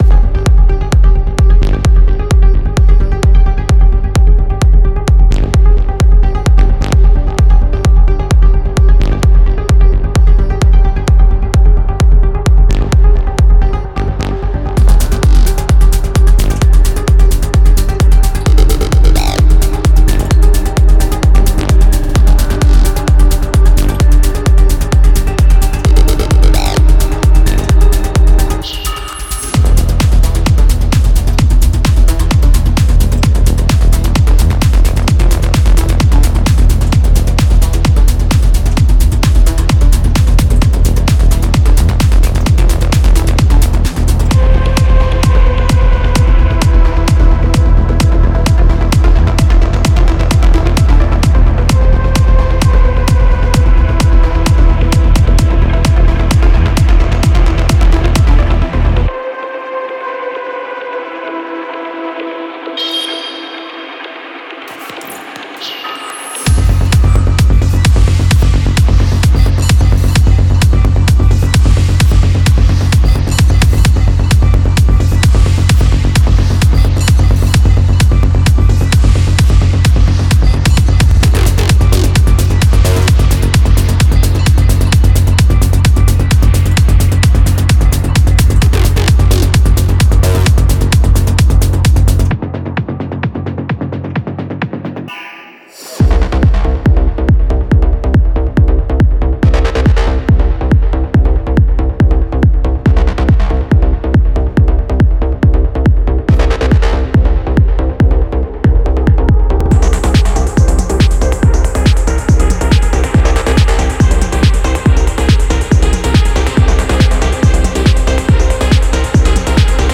Genre:Techno
Bass Loops -> 厚みがあり、催眠的でフックの効いたシンコペーションベースライン。
FX Loops -> 波打つようなアトモスフィアとテクスチャーが揃う。
FX One-Shots -> 近未来的なインパクト音、ライザー、フィルが現代的なローリング・サウンドを演出。